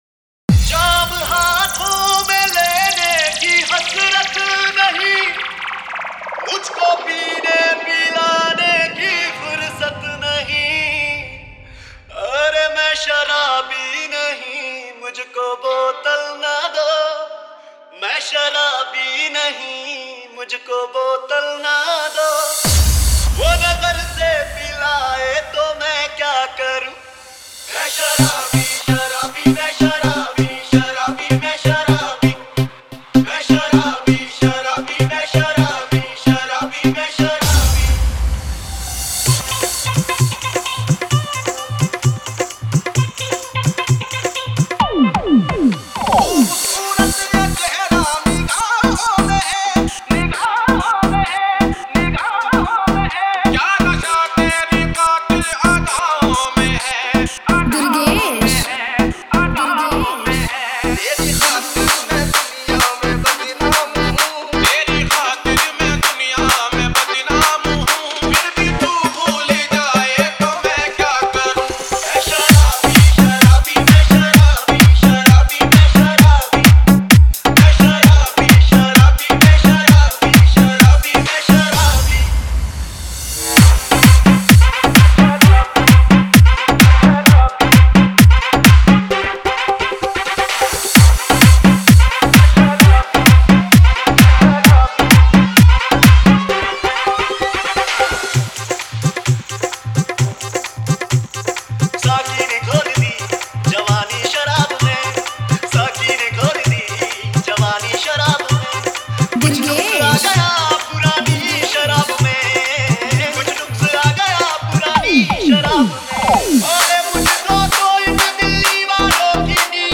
Bollywood Dj Remix Songs